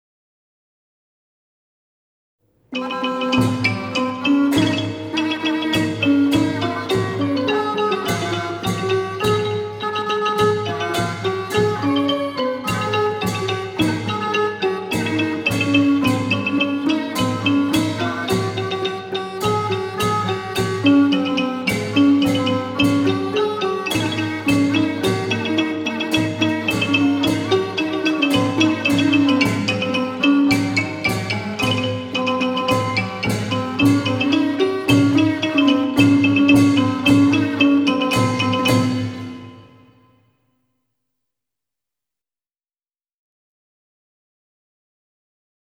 เพลงมหาฤกษ์ (วงปี่พาทย์-ไม้แข็ง)